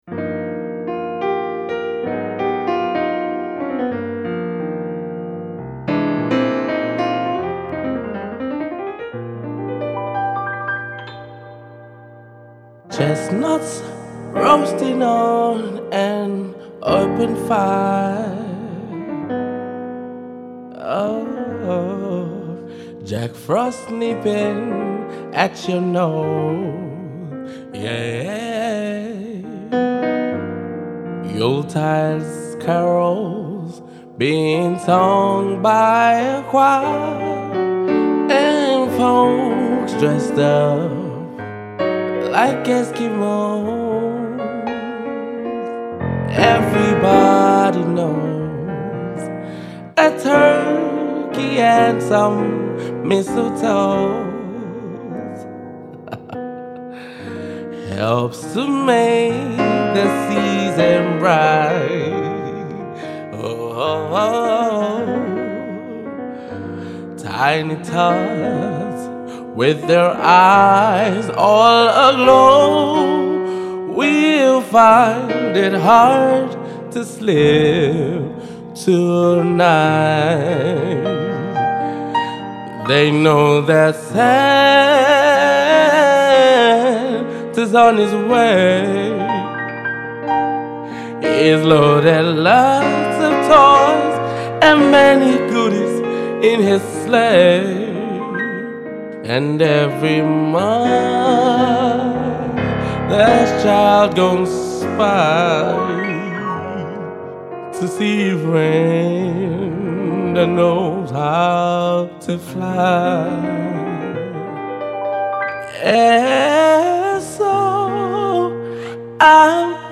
mellow piano rendition